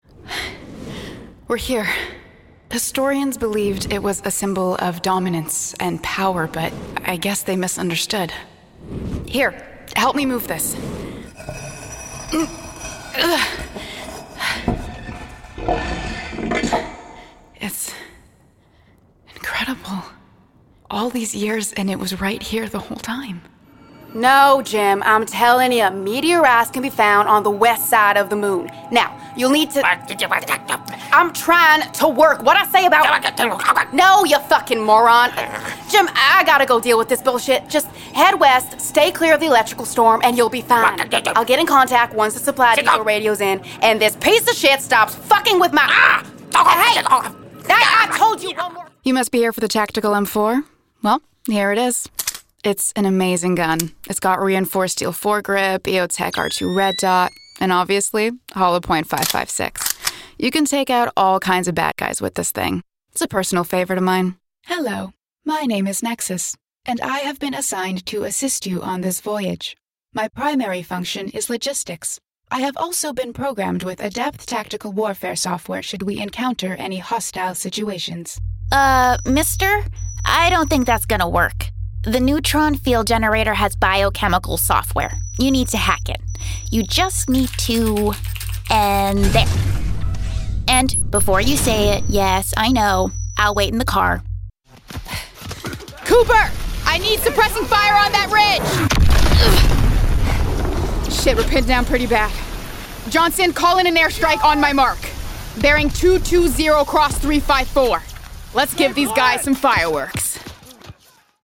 Jeux vidéo - ANG